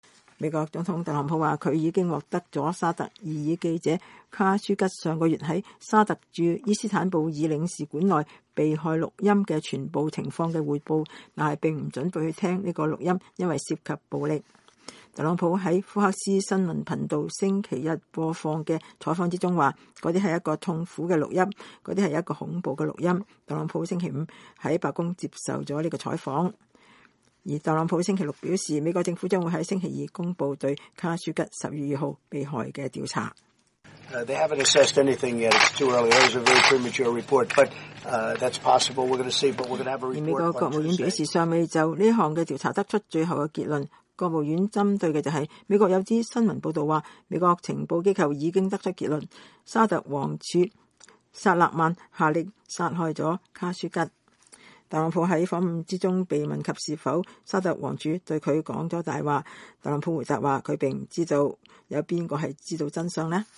美國總統特朗普17日對媒體講話。